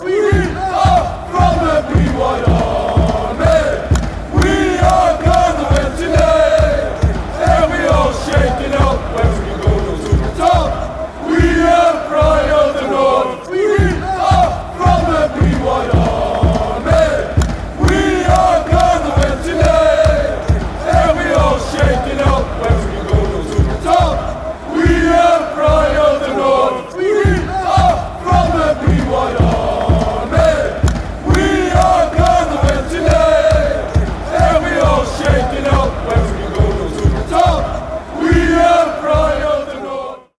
Singing along with the Pride of the North
Football Songs